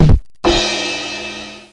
Good Joke Sound Effect